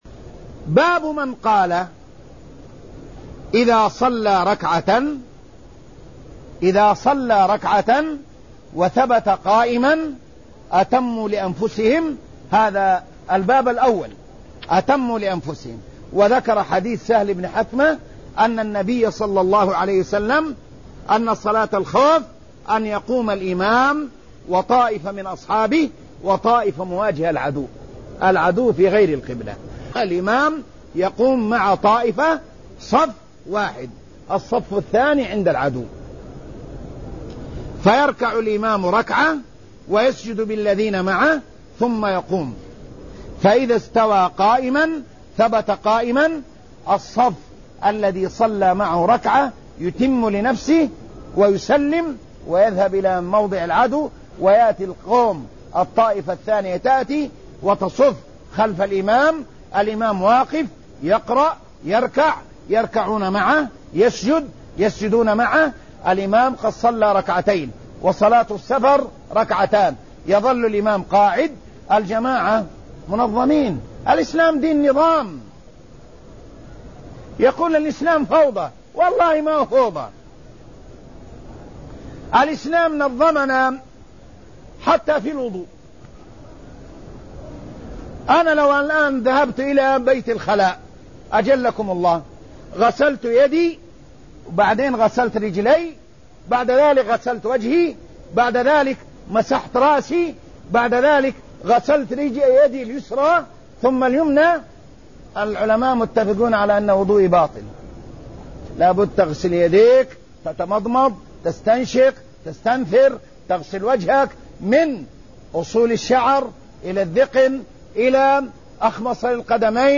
المكان: المسجد النبوي الشيخ